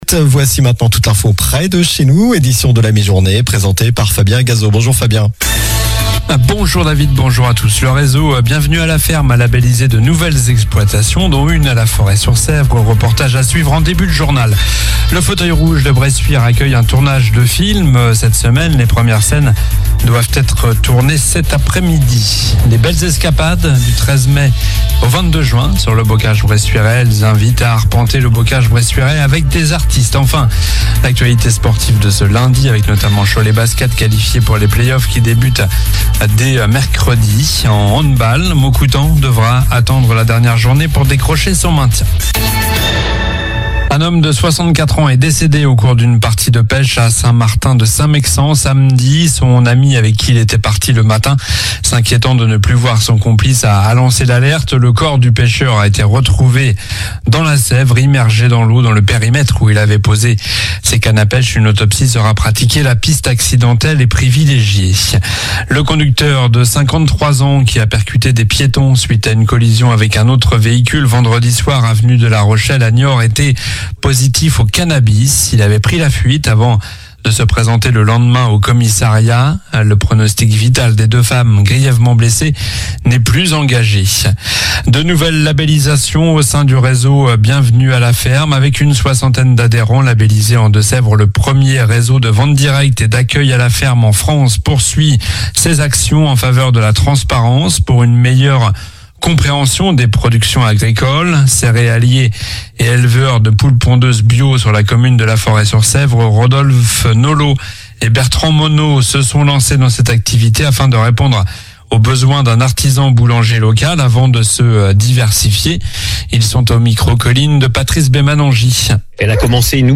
Journal du lundi 13 mai (midi)